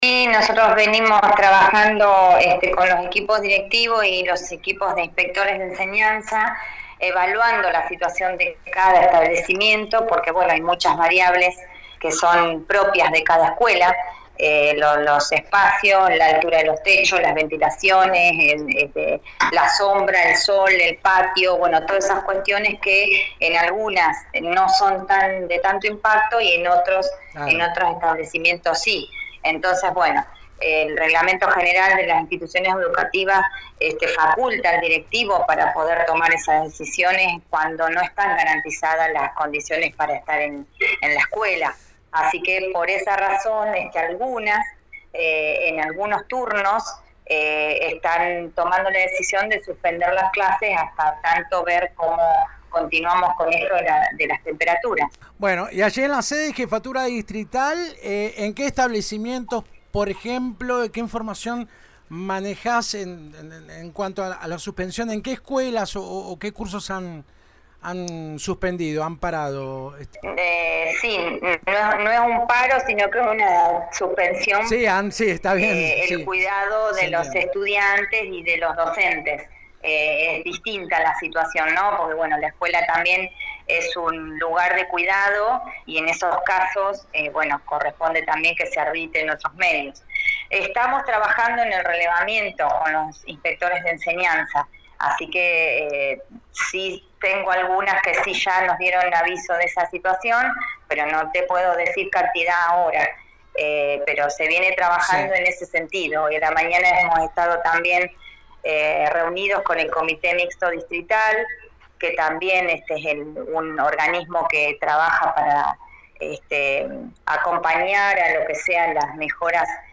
«Vamos a evaluar la situación día a día aunque ya sabemos que el calor seguirá hasta el jueves al menos. En aquellas escuelas donde se dificulte dar clases por la ventilación es imprescindible por la salud de los chicos y docentes suspender», explicó este lunes a FM Alpha la Jefa Distrital de Educación, Prof. Alfonsina Ordoñe.